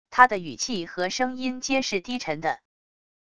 他的语气和声音皆是低沉的wav音频生成系统WAV Audio Player